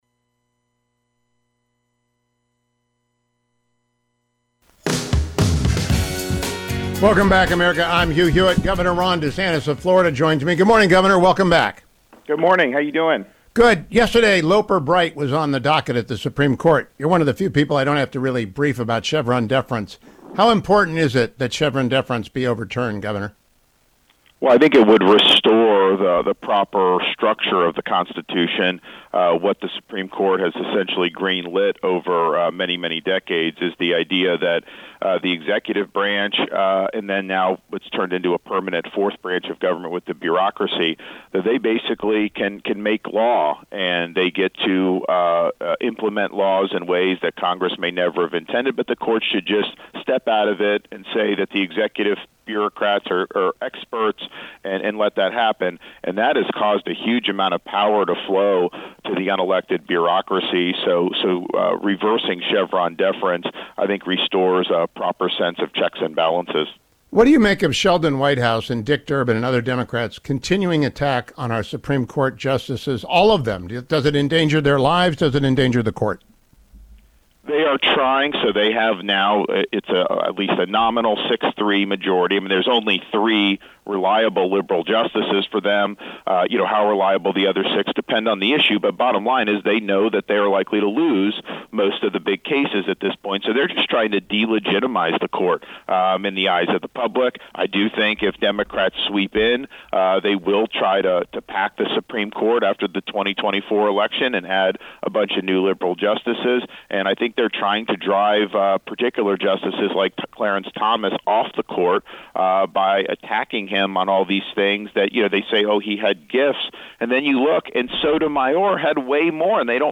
Florida Governor Ron DeSantis joined me this AM: